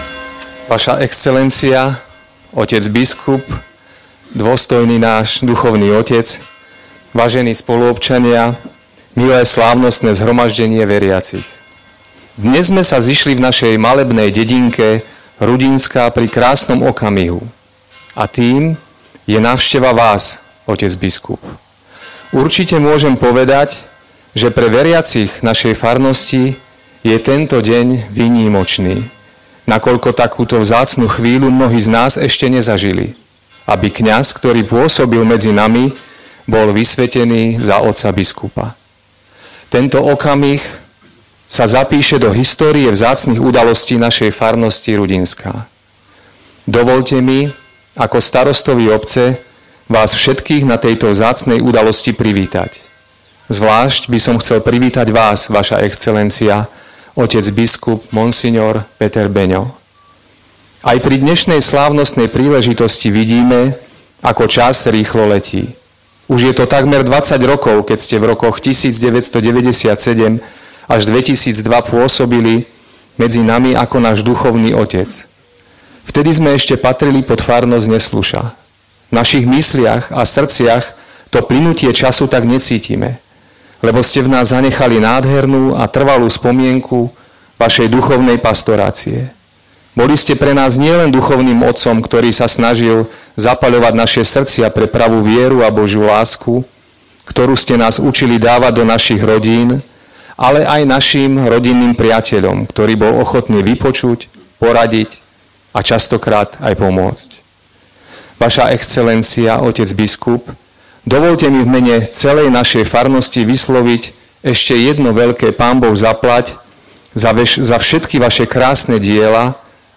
Privítanie pán starosta - klikni sem